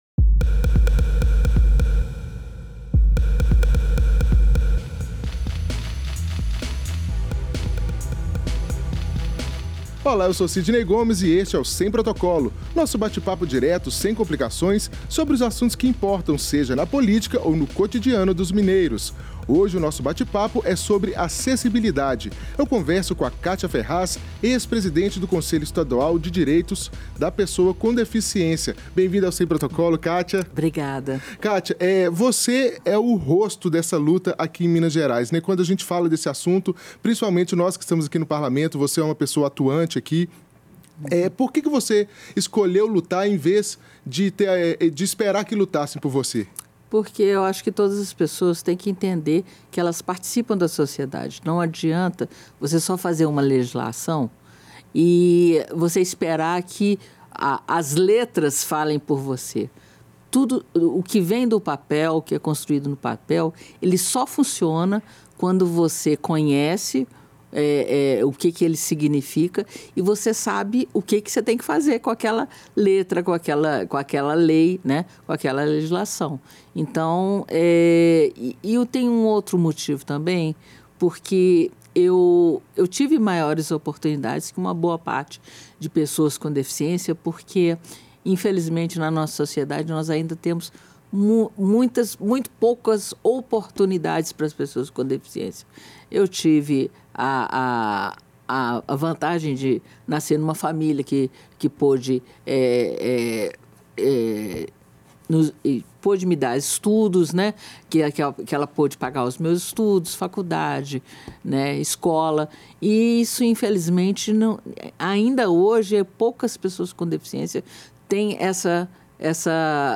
Neste bate-papo, ela destaca o pioneirismo da Assembleia Legislativa ao criar, há 25 anos, a Política Estadual e o Conselho Estadual pelos direitos PCD, e comemora os 10 anos da Lei Nacional de Inclusão, mas sem perder de vista que é preciso avançar ainda mais.